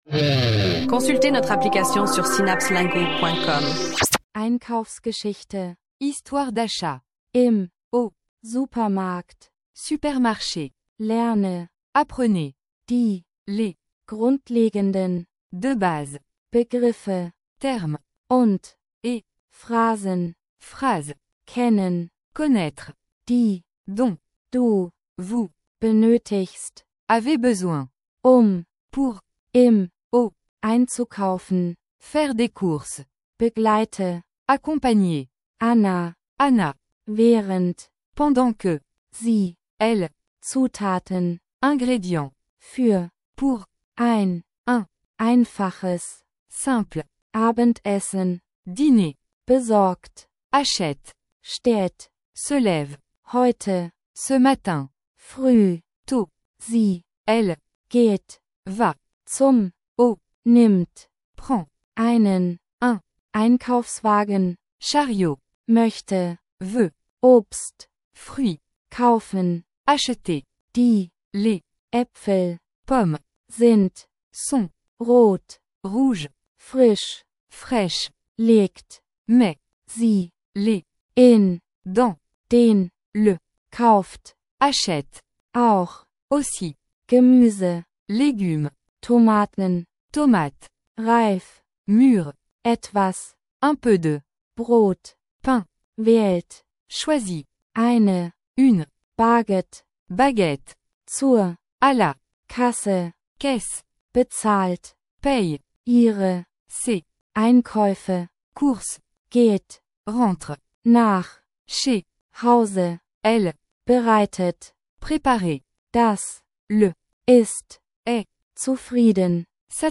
Plongez dans un monde passionnant d’apprentissage des langues avec des textes d’apprentissage divertissants et des chansons entraînantes dans différentes combinaisons de langues.
Écoutez, apprenez et profitez de mélodies accrocheuses qui vous aideront à maîtriser la langue de manière ludique.